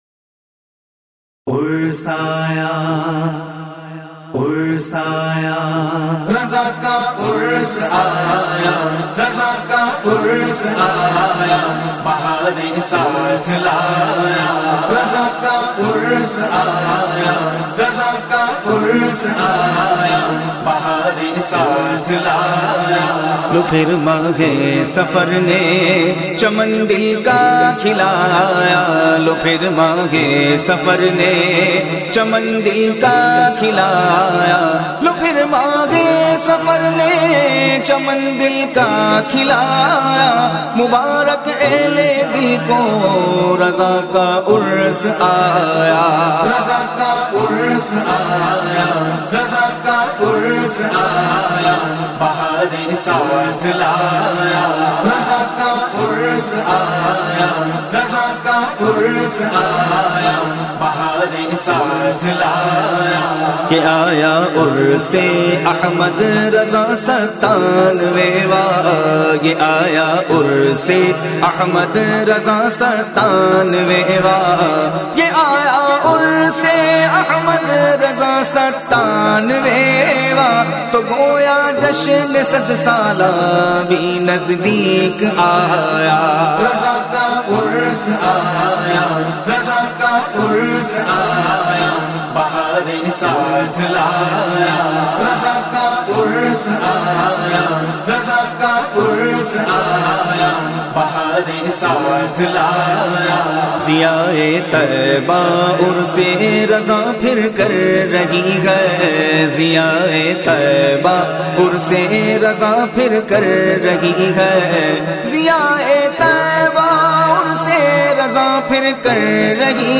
A Kalam